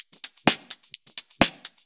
Index of /VEE/VEE2 Loops 128BPM
VEE2 Electro Loop 128.wav